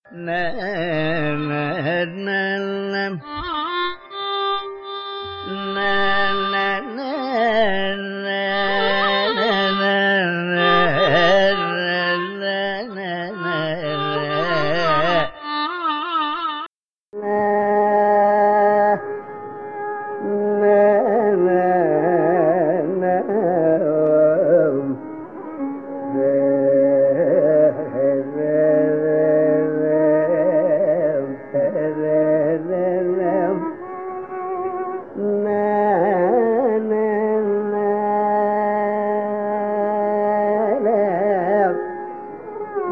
I find the first one surprisingly tricky in isolation (considering what it is) as it speaks of the similarities rather than differences.
I also feel it is Anandabhairavi and mAnji.